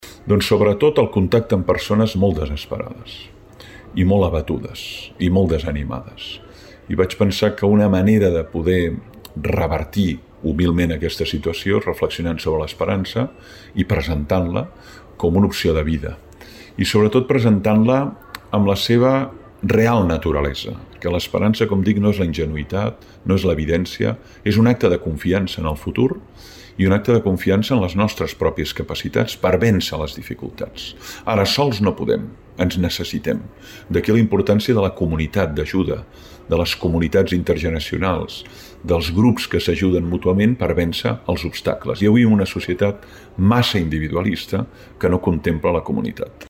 La Biblioteca Francesc Pujols s’ha omplert de lectors i amants de la filosofia amb motiu de la presentació del llibre Anatomia de l’esperança, la nova obra del pensador Francesc Torralba.
Francesc Torralba, autor 'Anatomia de l'esperança'